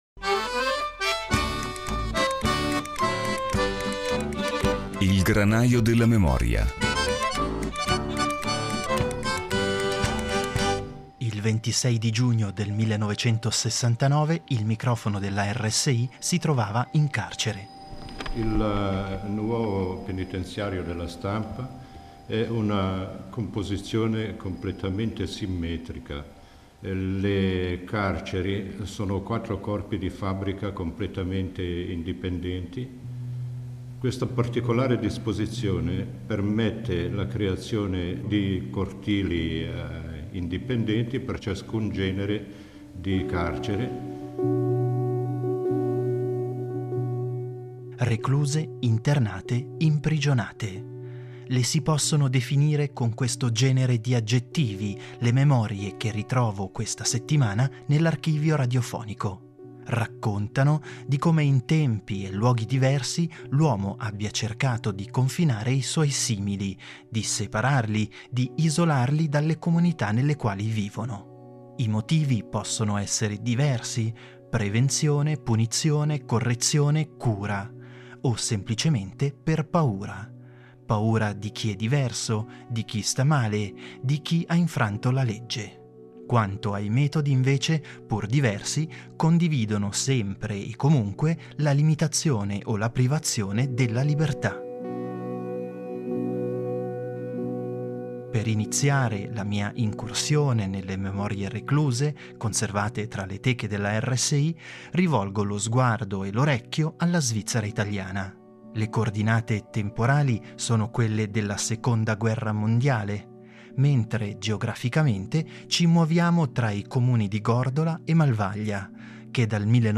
Le si possono definire con questo genere di aggettivi le memorie ritrovate questa settimana tra le teche della RSI. Saranno in effetti storie di confinamento e di isolamento a riaffiorare dall’archivio sonoro svizzero italiano.